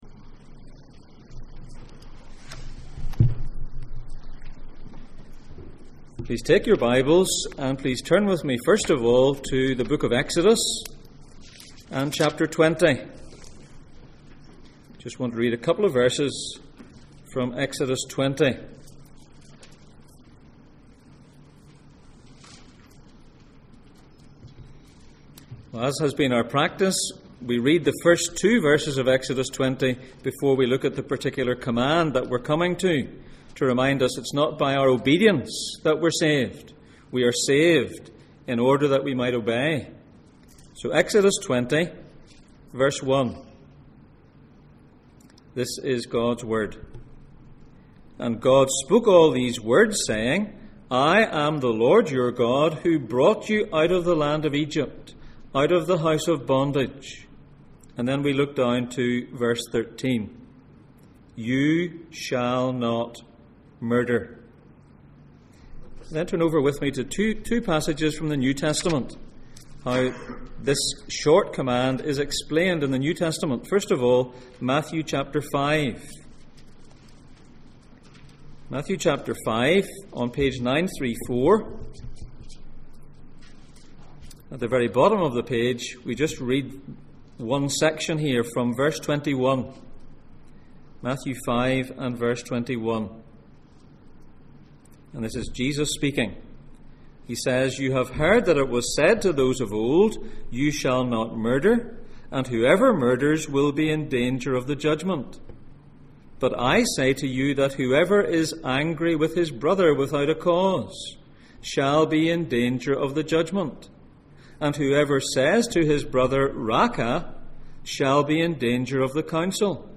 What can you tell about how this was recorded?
Gods instructions for life Passage: Exodus 20:1-2, Exodus 20:13, Matthew 5:21-26, 1 John 3:15-20, Exodus 21:12, Exodus 22:1-2, Genesis 9:1-3, Genesis 9:6 Service Type: Sunday Morning